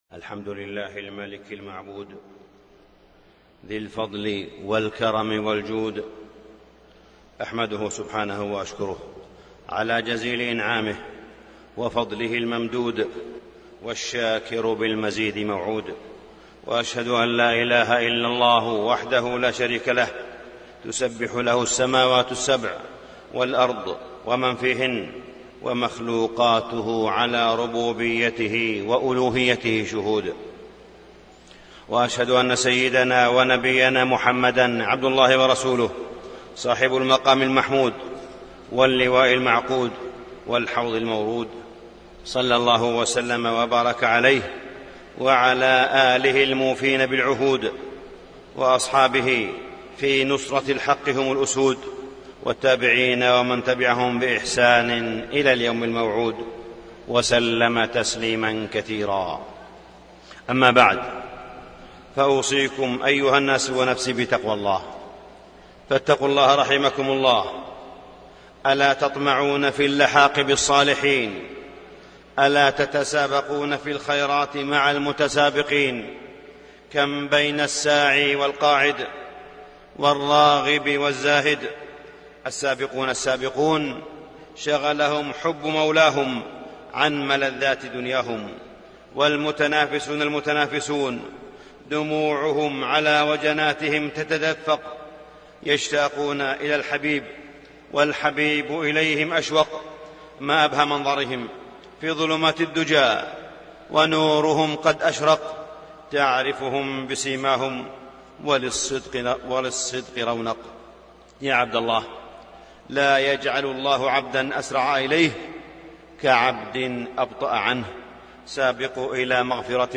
تاريخ النشر ٢٢ رمضان ١٤٣٣ هـ المكان: المسجد الحرام الشيخ: معالي الشيخ أ.د. صالح بن عبدالله بن حميد معالي الشيخ أ.د. صالح بن عبدالله بن حميد مؤتمر القمة